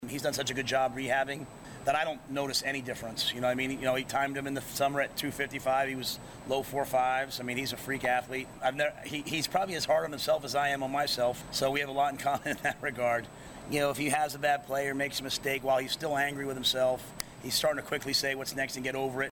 NEBRASKA FOOTBALL TUESDAY PRACTICE COMMENTS – MATT RHULE